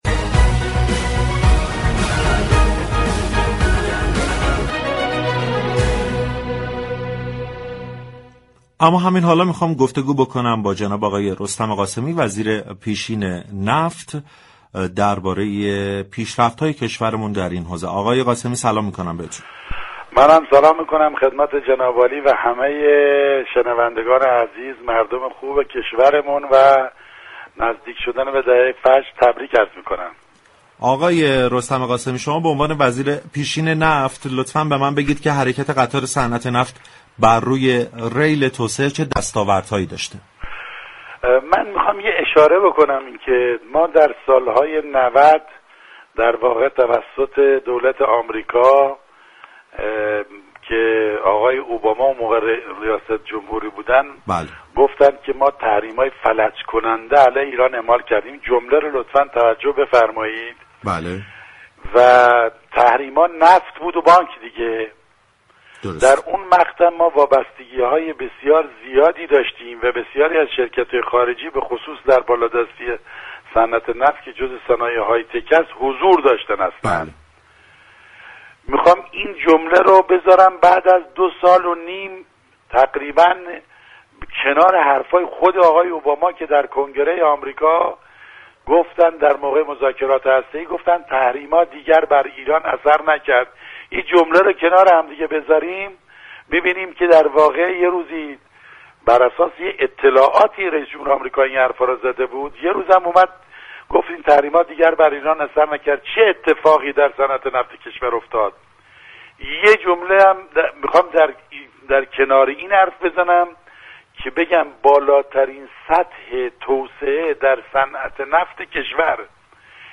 به گزارش روابط عمومی رادیو تهران، رستم قاسمی وزیر پیشین نفت در گفتگو با برنامه بازار تهران ضمن تبریك فرارسیدن دهه فجر، درباره دستاوردهای نفتی ایران در اوج تحریم های اقتصادی با اشاره به اظهارات باراك اوباما رئیس جمهوری اسبق آمریكا گفت: در سال های 90 توسط دولت آمریكا كه باراك اوباما سكاندار آن بود تحریم نفتی و بانكی شدیم.